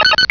Cri de Togetic dans Pokémon Rubis et Saphir.